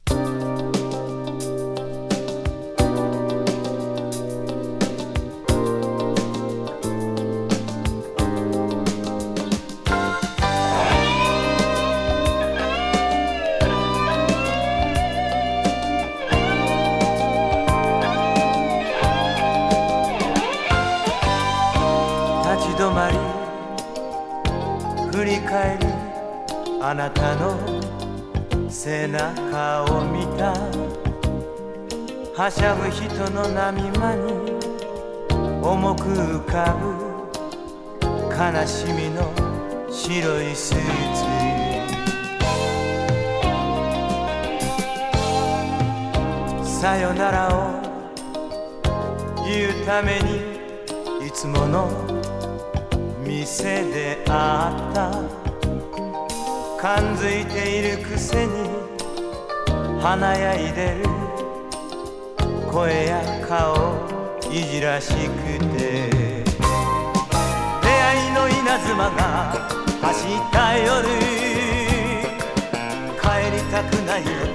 アンニュイ・メロウ歌謡
歌の上手さも流石です。